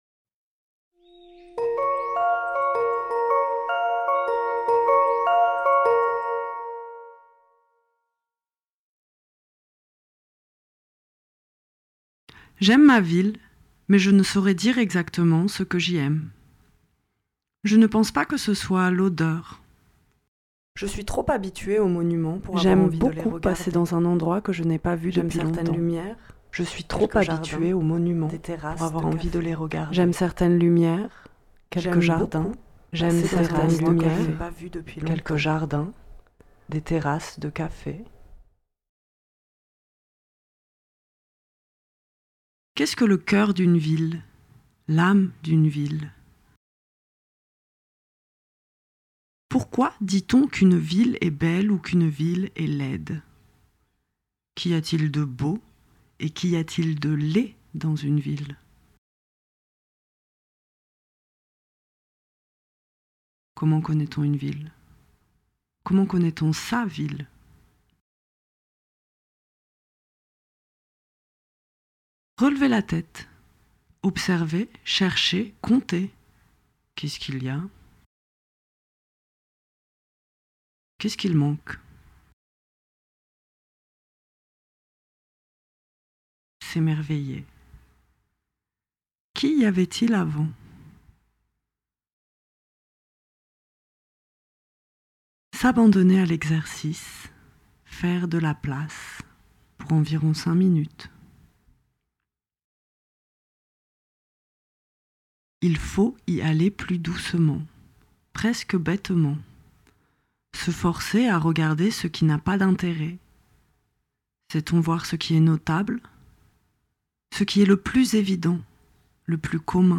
Création sonore : « Déchiffrer un morceau de ville »